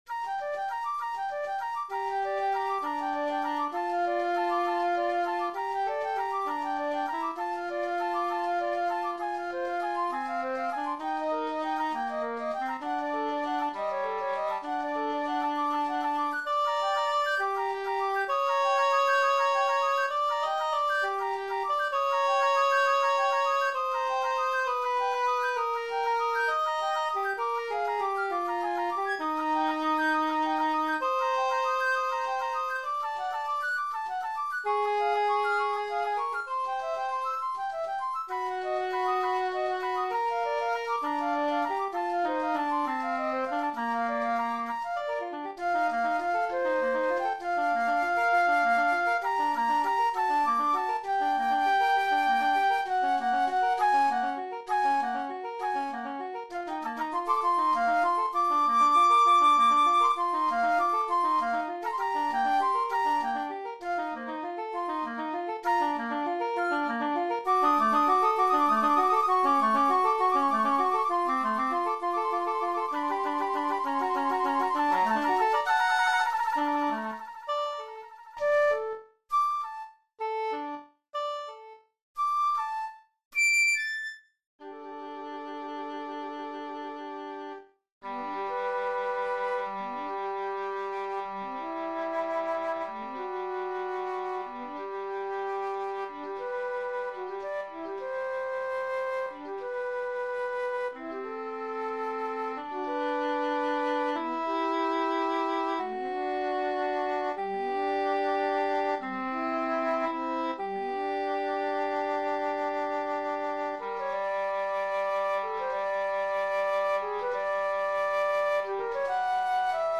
Moderato
1 C flute or Alto flute
1 English Horn